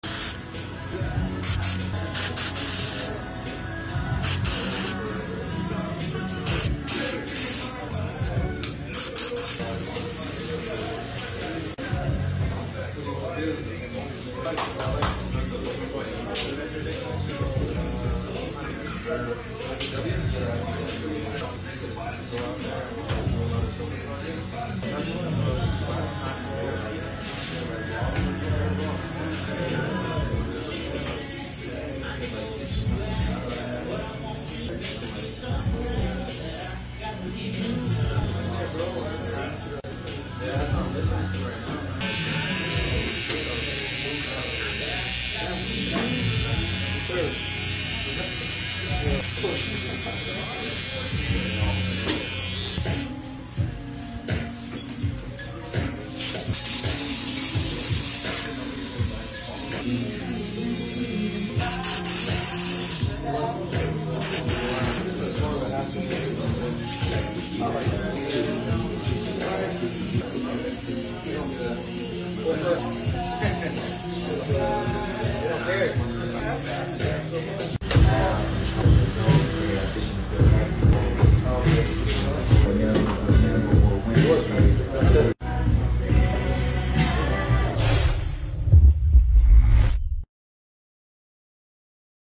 Barber Enhancement Tutorial 💈 ASMR sound effects free download